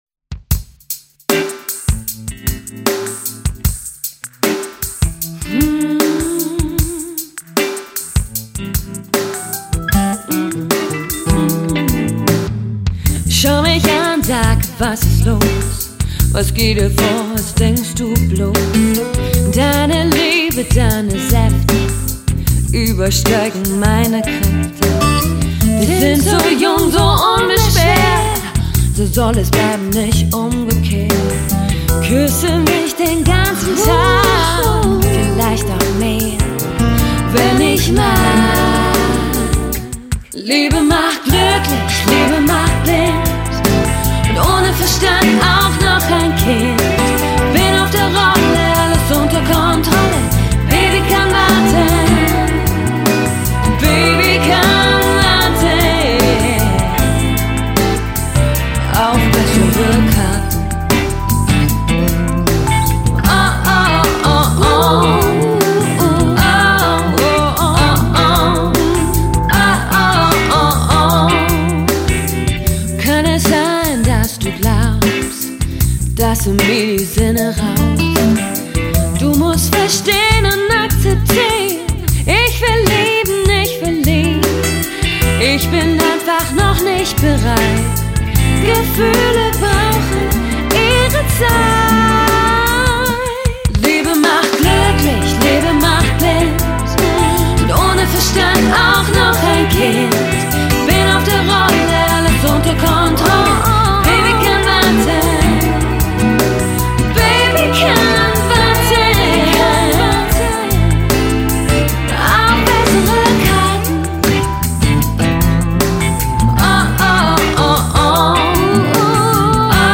Genre: Kampagnen-Musik